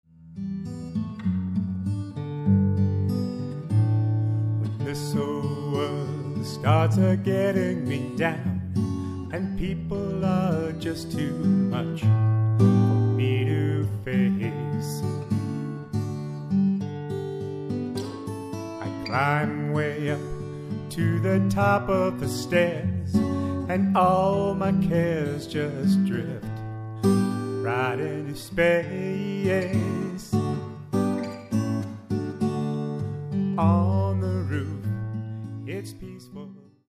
Background Guitar & Vocals
sample of guitar and vocal music here.